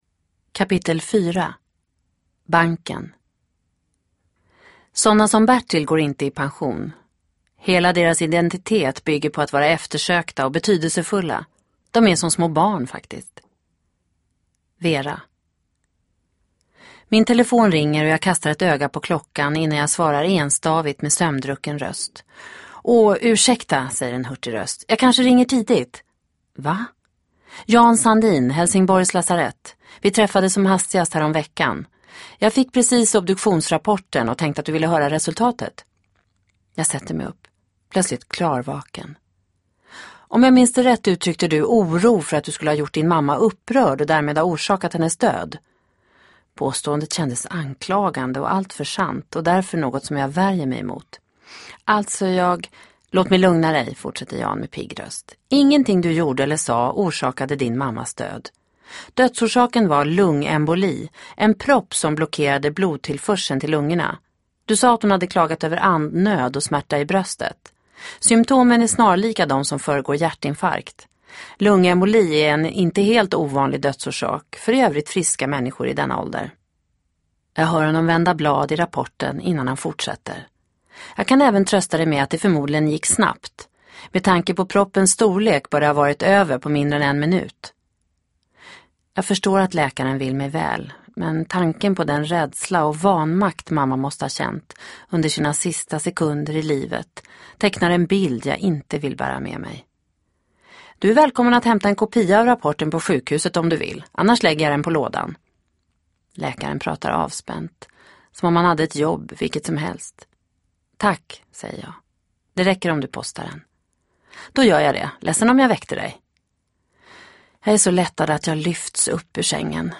Hemvändaren del 4 – Ljudbok